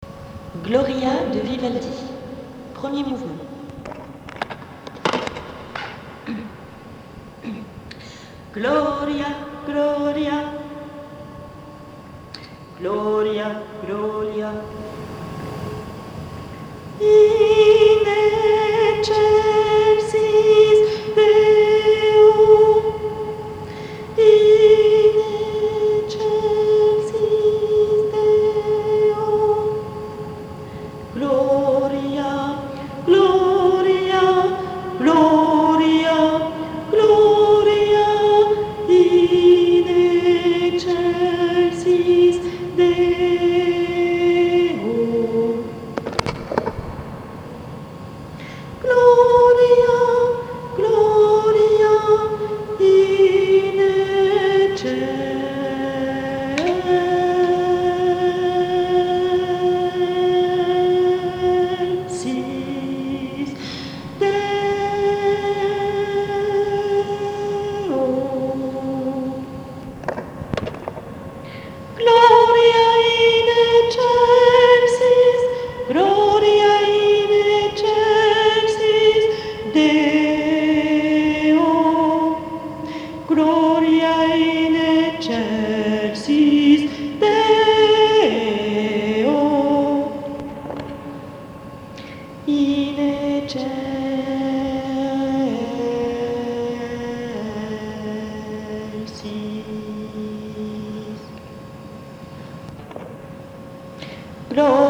Pupitre BASSE